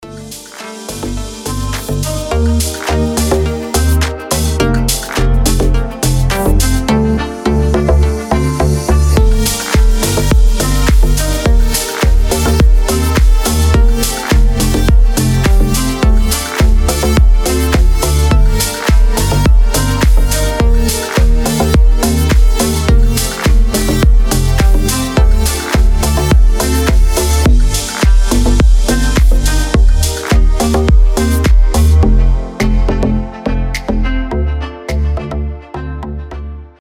• Качество: 320, Stereo
deep house
мелодичные
без слов
релакс
Красивая дип музыка с грустным мотивом